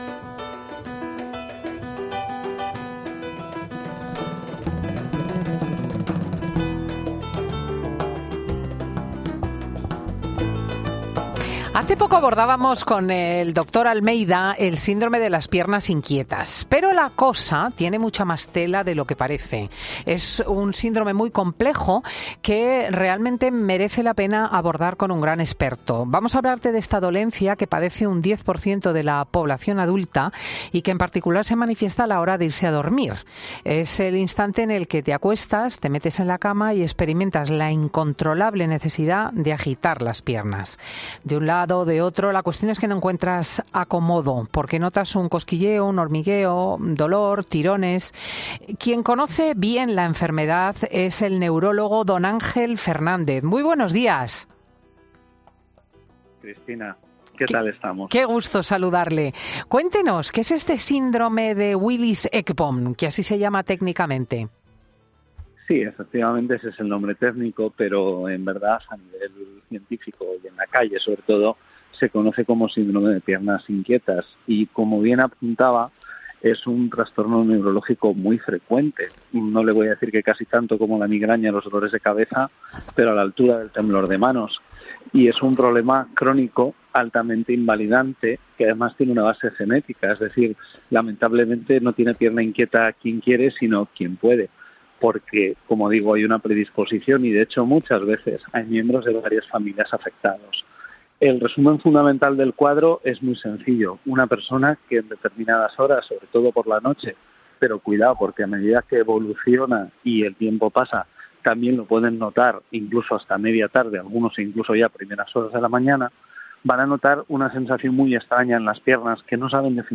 Redacción digital Madrid - Publicado el 02 dic 2023, 14:20 - Actualizado 02 dic 2023, 14:28 2 min lectura Descargar Facebook Twitter Whatsapp Telegram Enviar por email Copiar enlace Escucha ahora 'Fin de Semana' . "Fin de Semana" es un programa presentado por Cristina López Schlichting , prestigiosa comunicadora de radio y articulista en prensa, es un magazine que se emite en COPE , los sábados y domingos, de 10.00 a 14.00 horas.